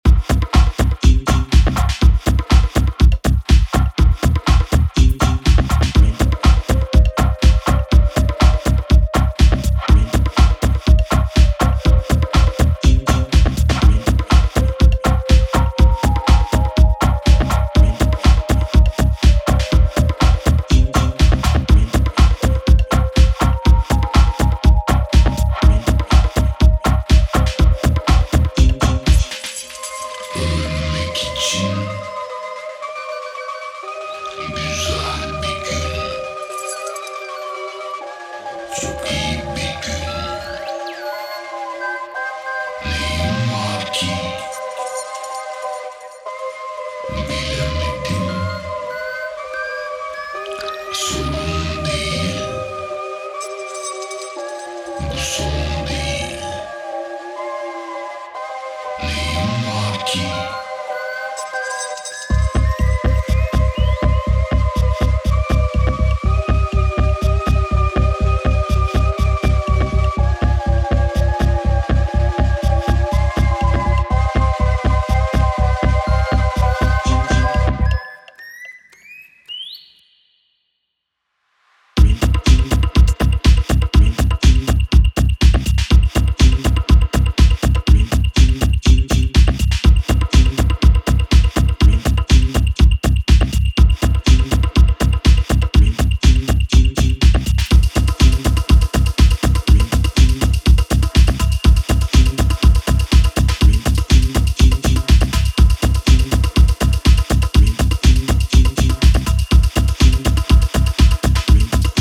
ディスコティークなベースラインを加えながらハメ度高く展開していくそちらも、申し分ないですね。